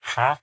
minecraft / sounds / mob / villager / haggle2.replace.ogg
haggle2.replace.ogg